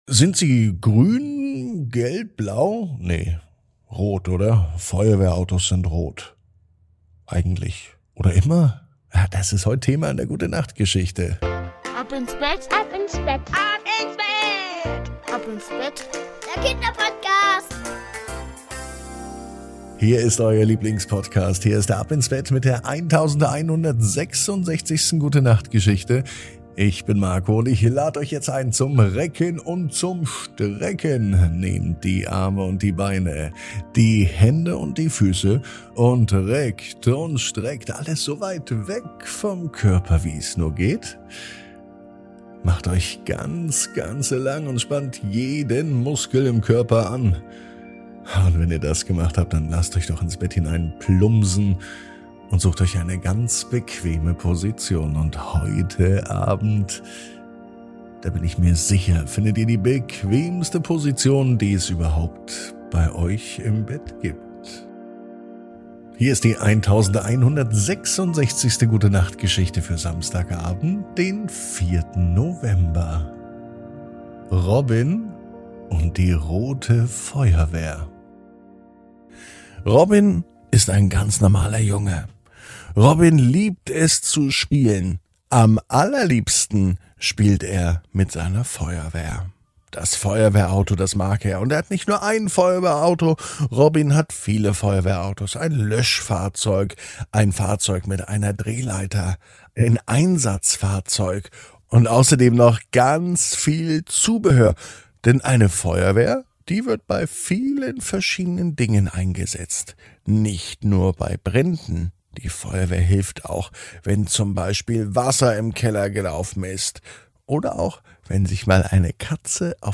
#1166 Robin und die rote Feuerwehr ~ Ab ins Bett - Die tägliche Gute-Nacht-Geschichte Podcast